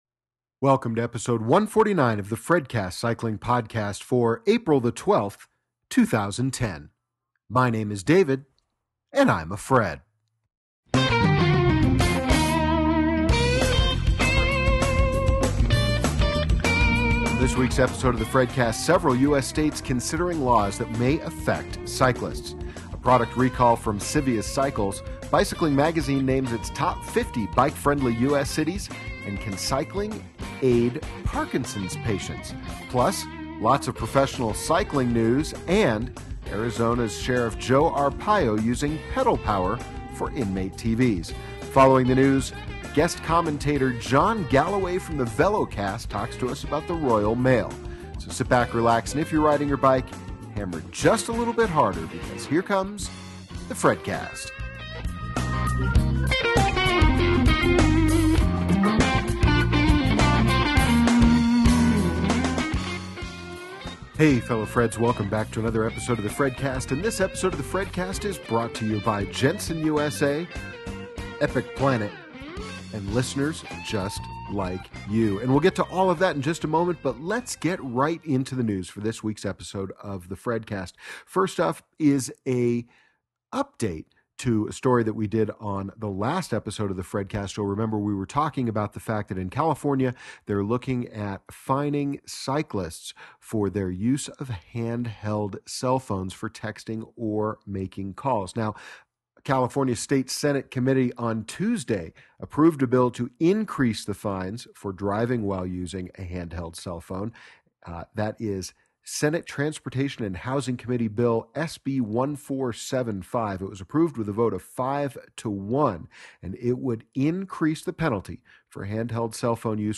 This episode of The FredCast Cycling Podcast features a guest commentary